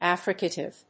音節af・fric・a・tive 発音記号・読み方
/æfríkəṭɪv(米国英語)/